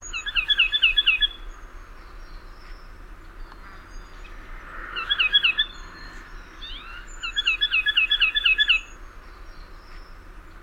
Anadolu sıvacısı: Türkiye'nin endemik kuşu. İğne yapraklı ormanlarda yaşar.